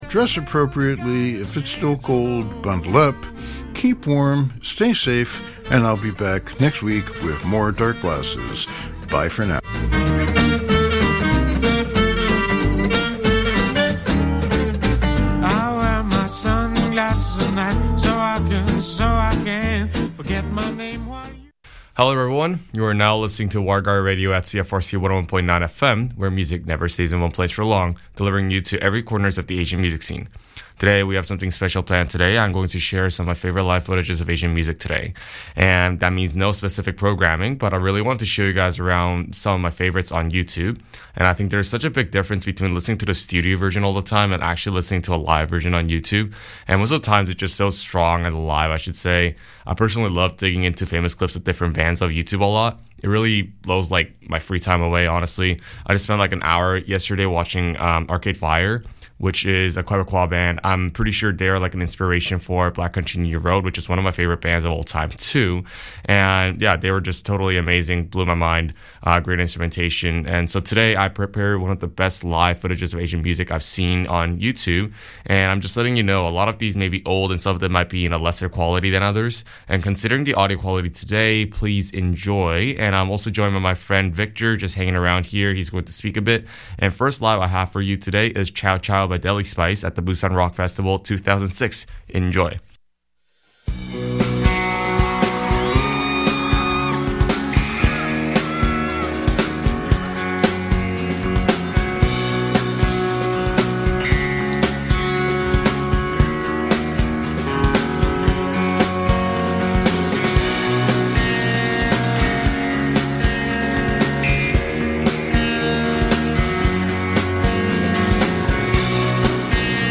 From 80’s Korean to Taiwanese Shoegaze and Japanese vocaloid, Warigari covers all sorts of music from the East Asian music scene.